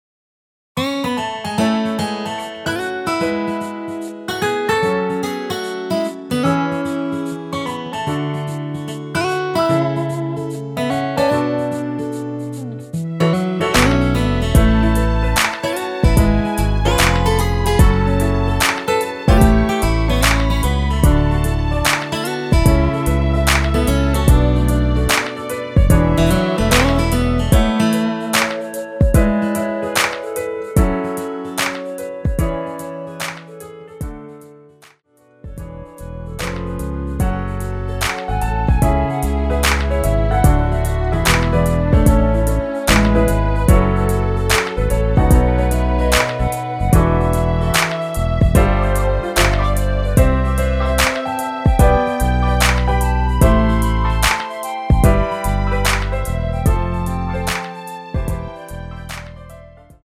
여자키 멜로디 포함된 MR 입니다.(미리듣기 참조)
앞부분30초, 뒷부분30초씩 편집해서 올려 드리고 있습니다.
중간에 음이 끈어지고 다시 나오는 이유는